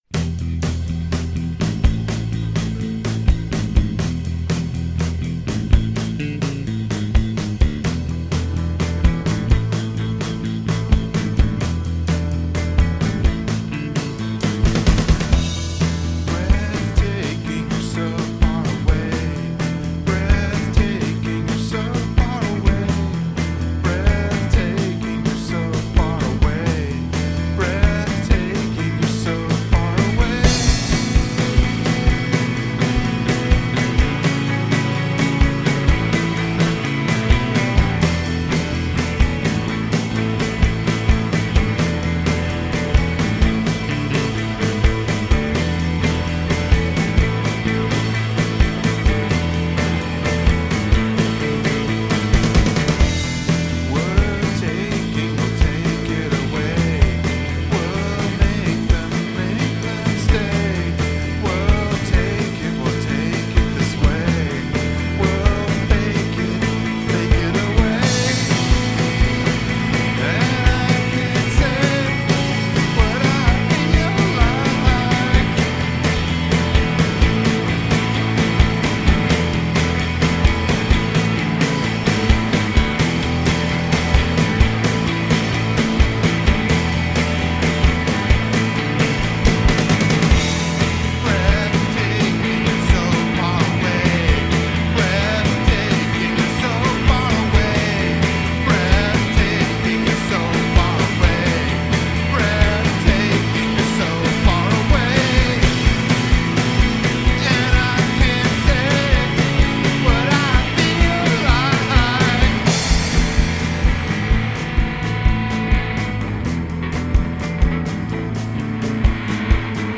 Doesn’t the vocalist have some kind of indeterminate accent?
The programming does not sound amateurish though.